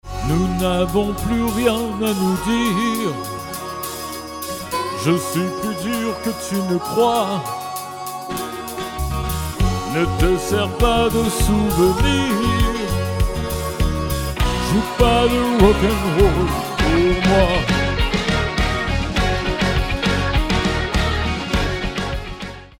Version chantée